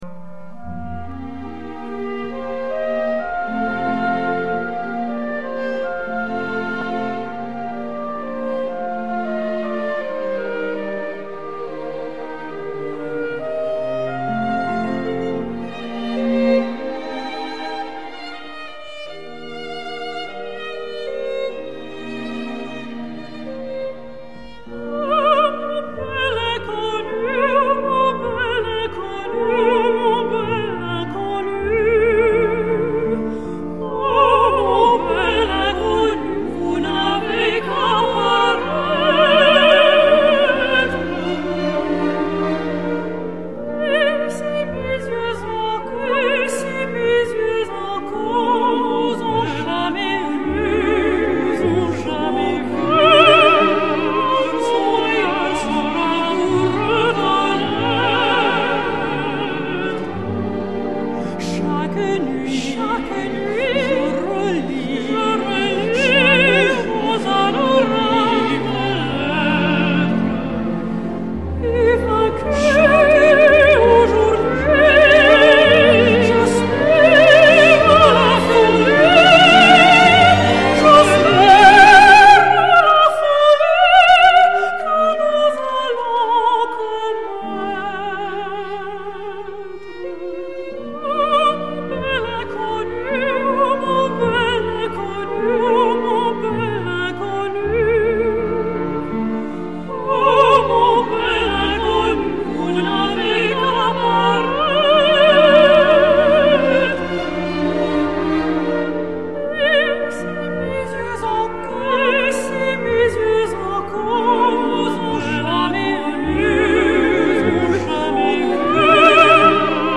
La comédie musicale